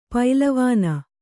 ♪ pailavāna